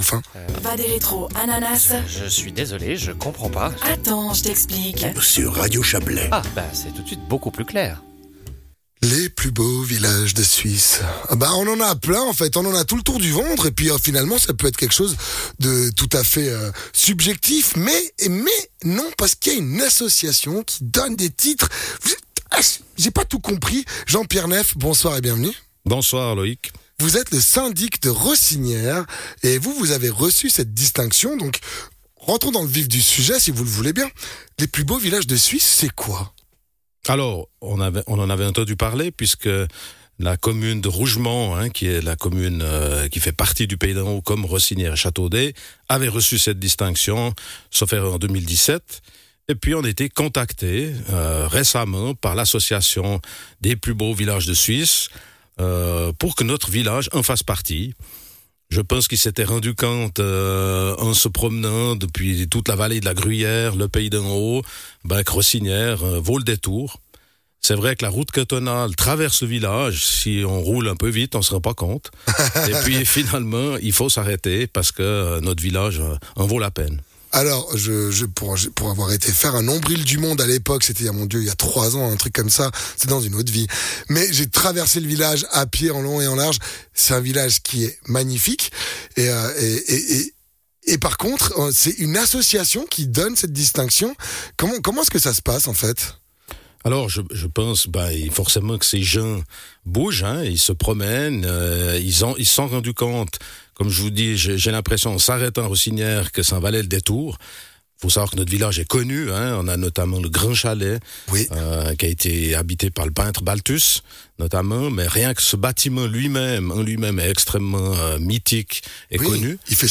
Sa commune a reçu cette distinction, Jean-Pierre Neff, Syndic de Rossinières, répond à la question.